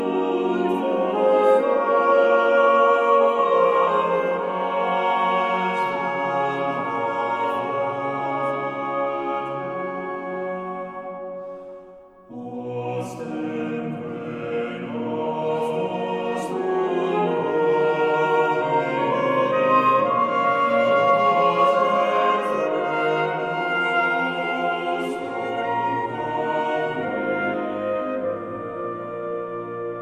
"enPreferredTerm" => "Musique vocale profane"